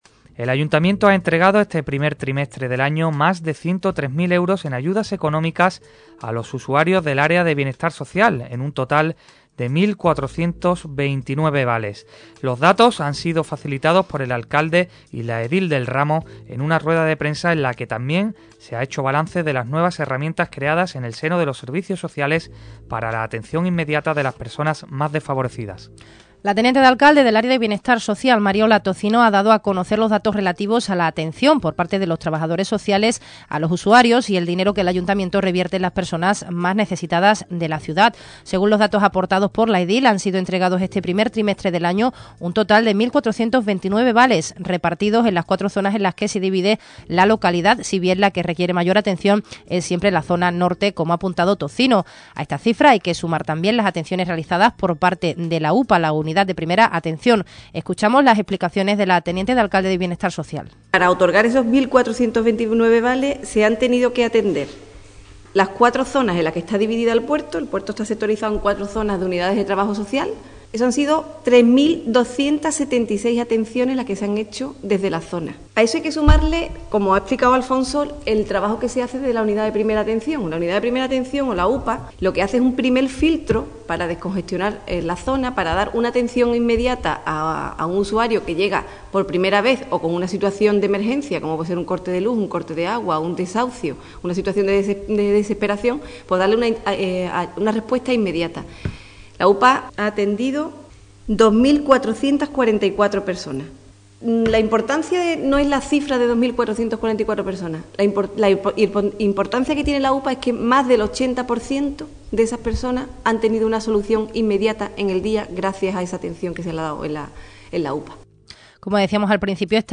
Ybarra ha explicado a Radio Puerto Emisora Municipal los nuevos costes, que beneficiarán sobre todo a los portuenses. La edil popular ha explicado que esta medida no se aplicará en los meses de julio y agosto, cuando los aparcamientos son utilizados sobre todo por turistas y visitantes.